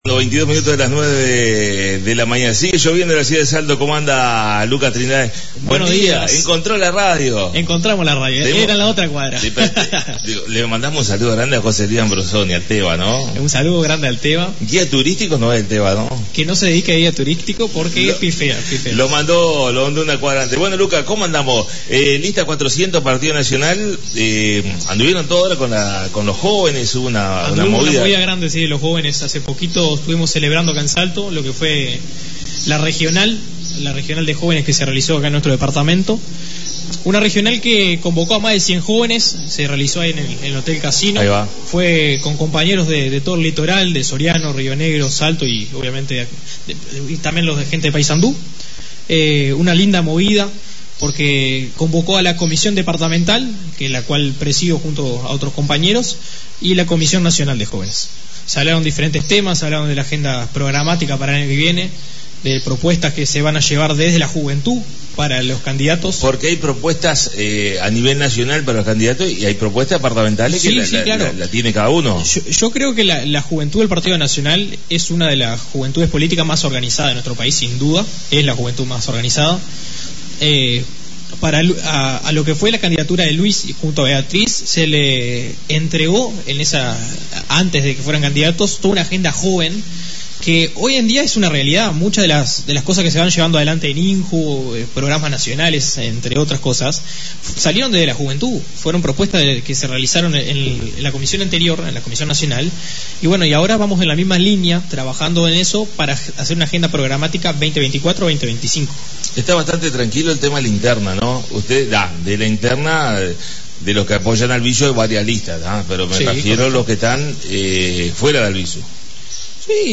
La visita a la Radio de Lucas Trindade, Edil y Representante de la Lista 400 en la Comisión Nacional de Jóvenes del Partido Nacional.